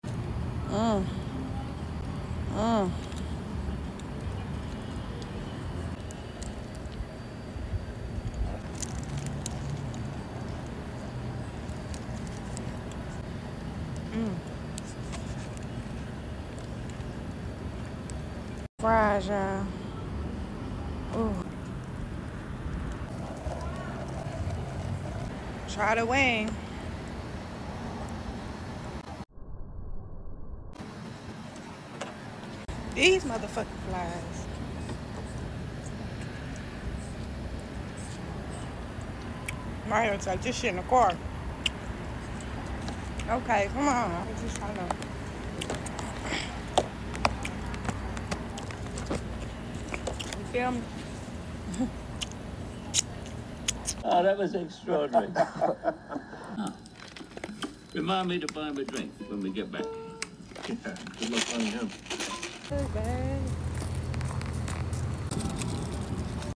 Funny food ASMR gone left sound effects free download
Outdoor dining OVER